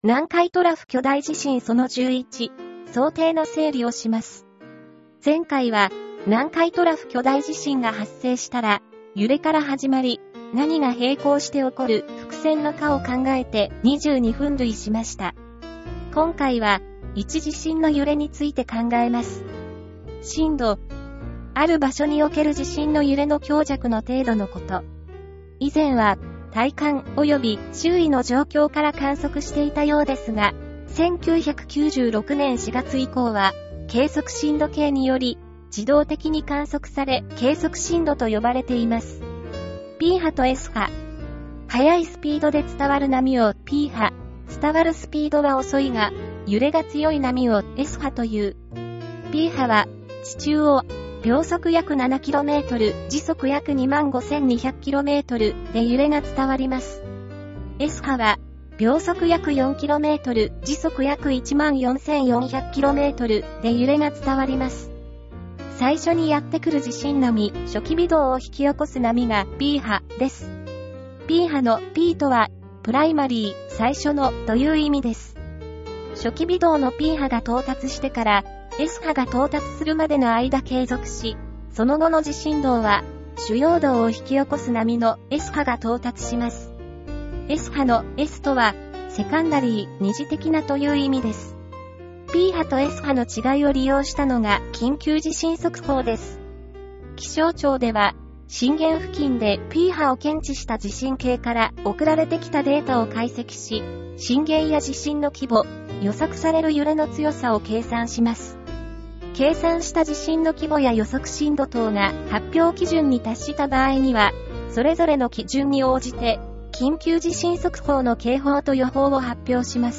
音声読み上げ